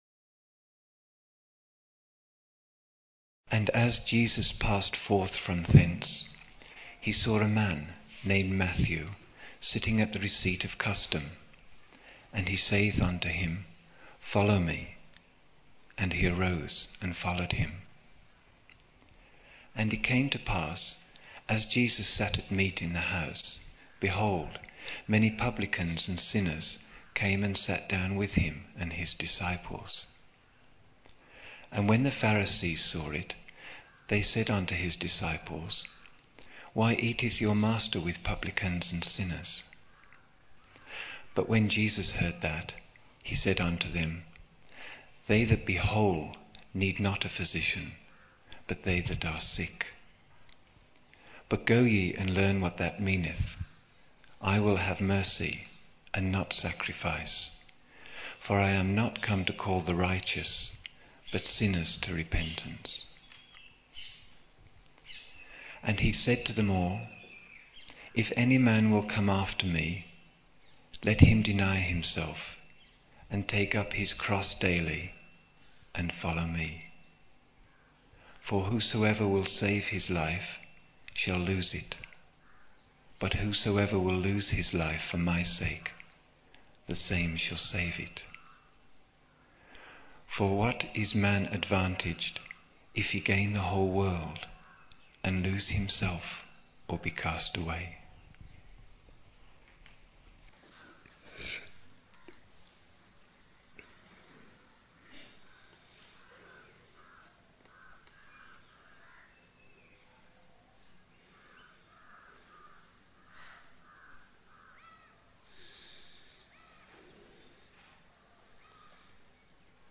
27 October 1975 morning in Buddha Hall, Poona, India